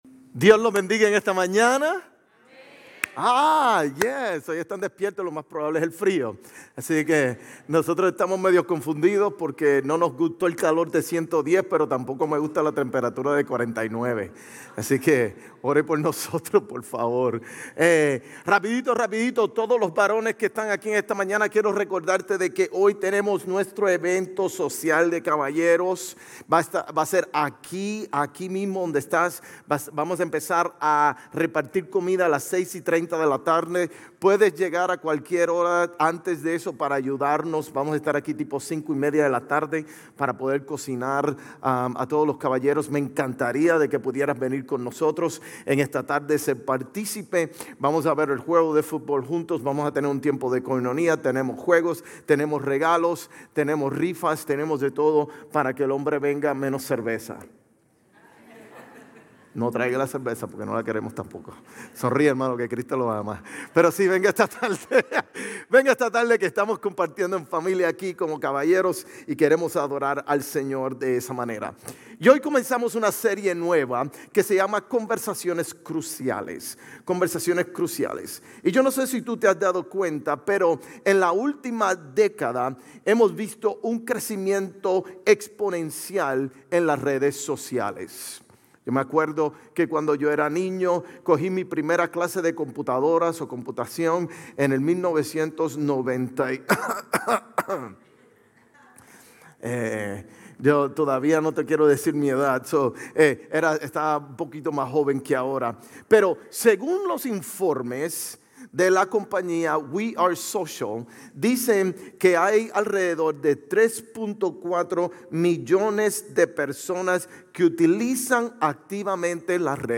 GCC-GE-October-8-Sermon.mp3